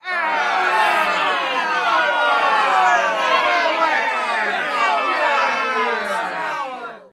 Play Angry Booing - SoundBoardGuy
Play, download and share Angry Booing original sound button!!!!
angry-booing.mp3